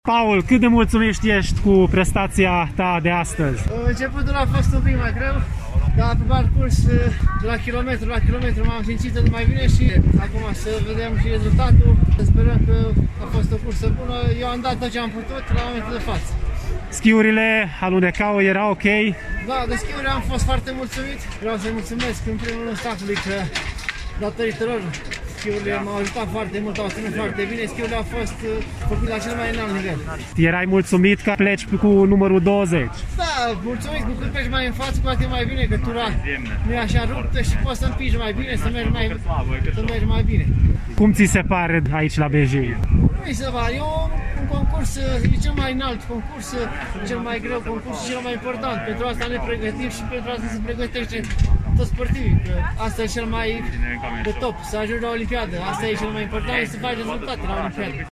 interviu audio